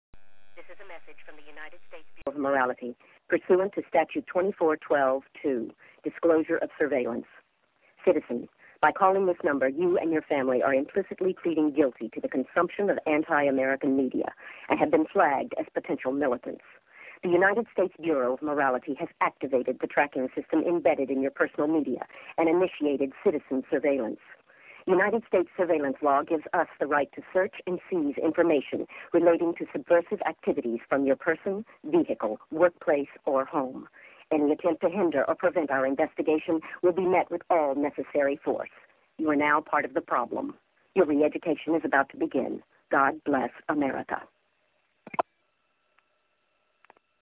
Recording of standard Bureau of Morality voice message.